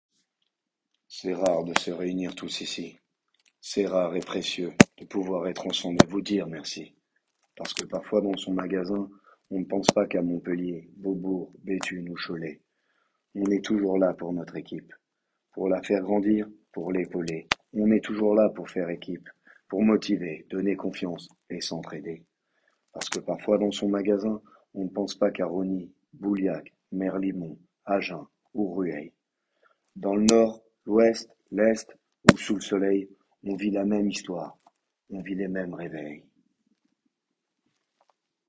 slam